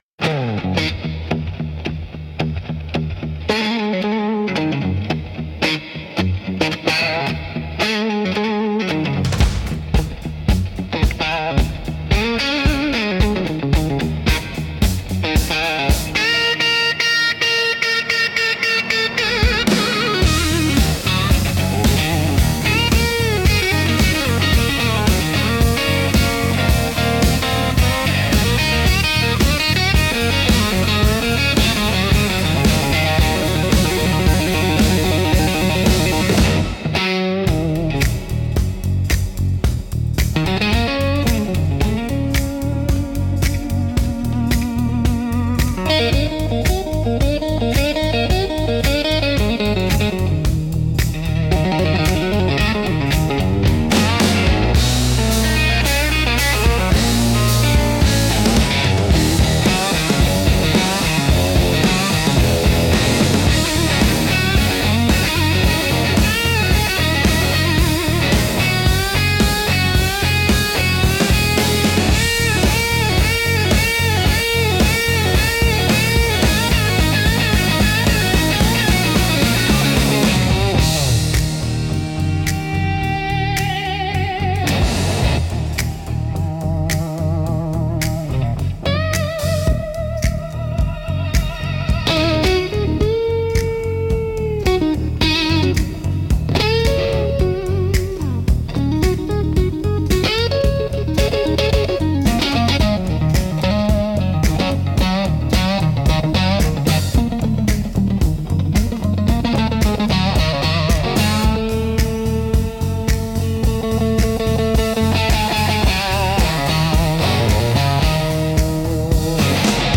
Instrumental - Dust and Overdrive